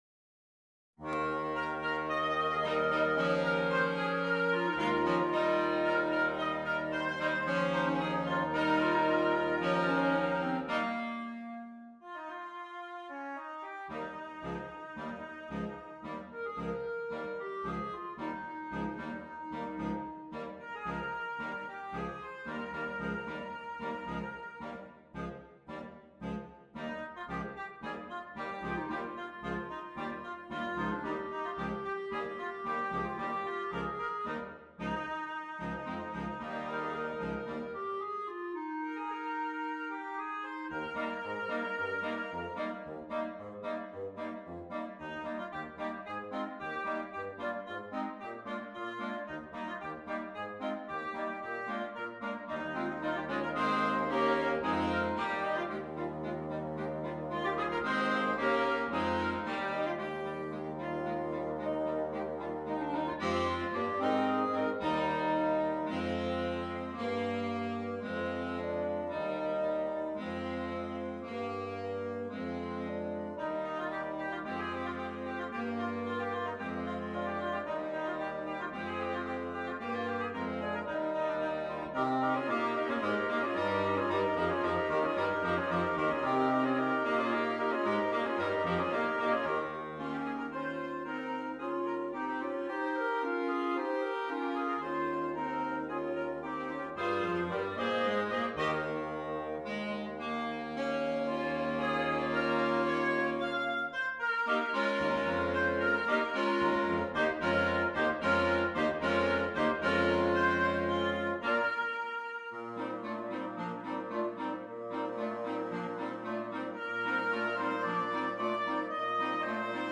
Interchangeable Woodwind Ensemble
PART 1 - Flute, Clarinet, Alto Saxophone
PART 3 - Clarinet, Alto Saxophone, Tenor Saxophone, F Horn
PART 5 - Baritone Saxophone, Bass Clarinet, Bassoon
Percussion (optional)